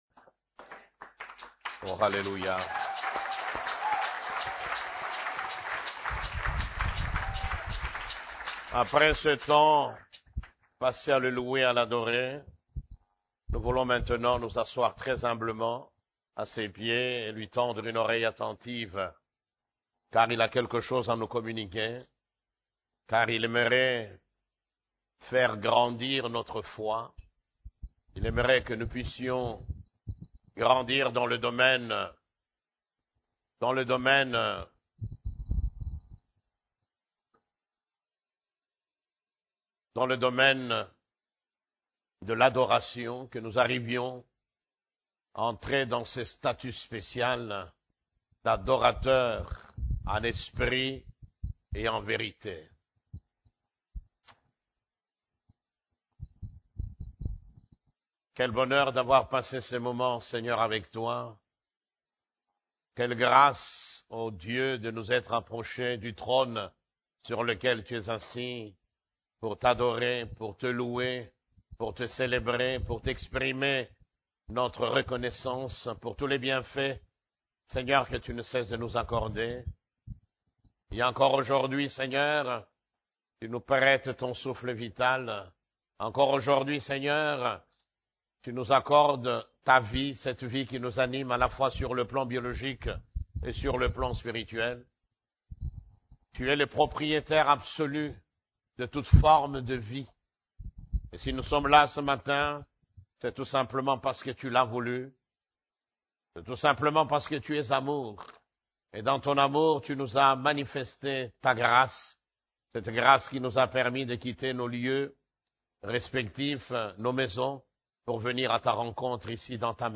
CEF la Borne, Culte du Dimanche, Comment voir l'invisible ? (4)